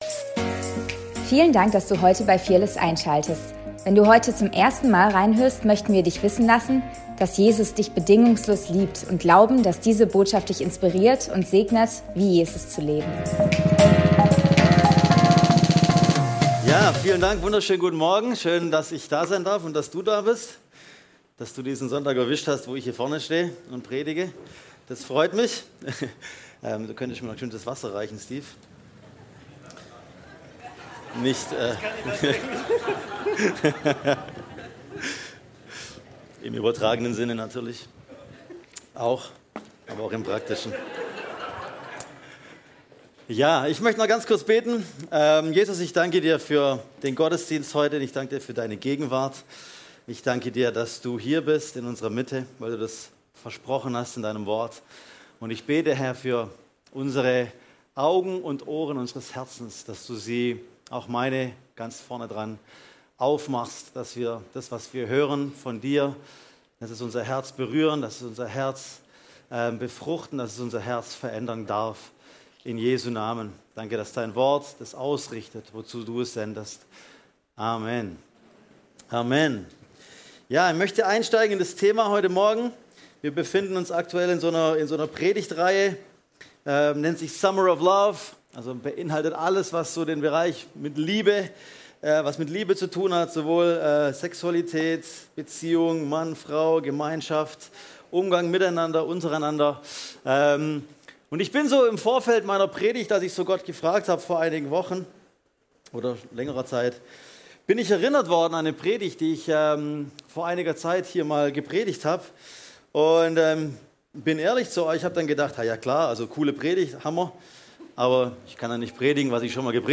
Predigt vom 11.08.2024